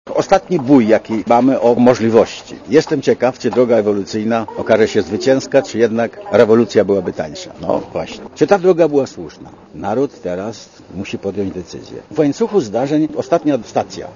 Komentarz audio (55Kb)